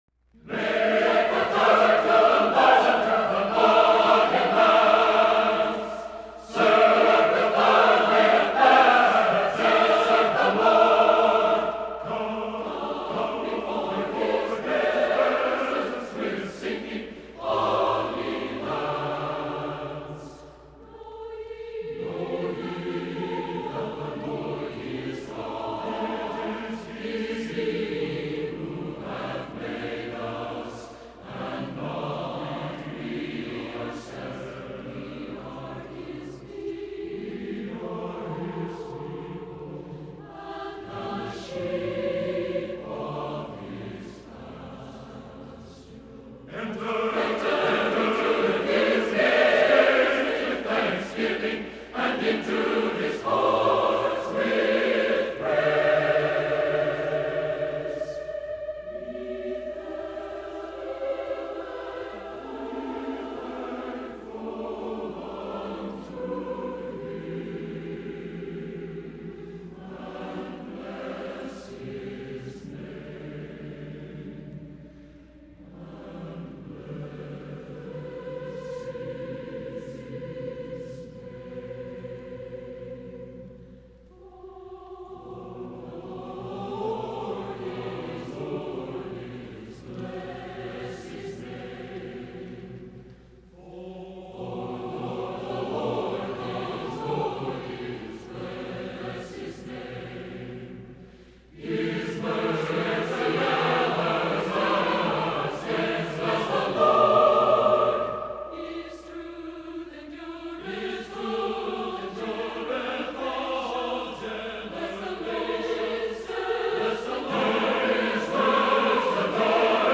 Epoque : 20ème s.
Genre-Style-Forme : Sacré ; Anthem ; Psaume
Type de choeur : SATB  (4 voix mixtes )
Tonalité : mi mineur